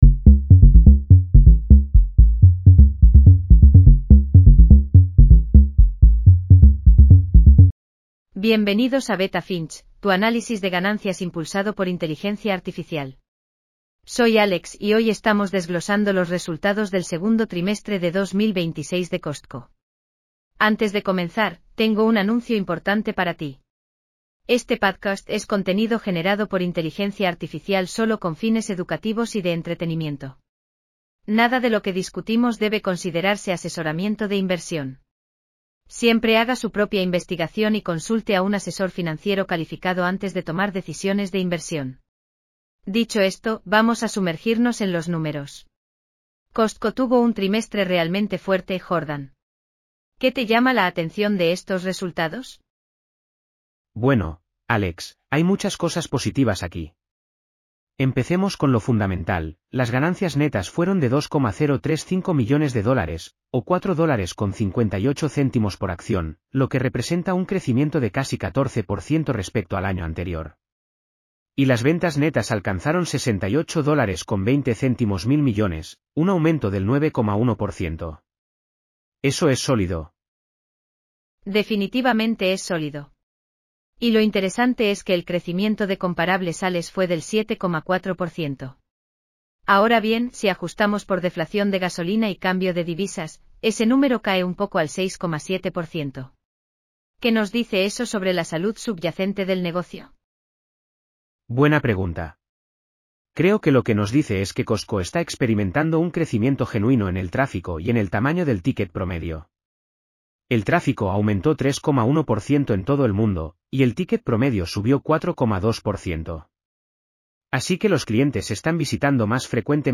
AI-powered earnings call analysis for Costco (COST) Q2 2026 in Español.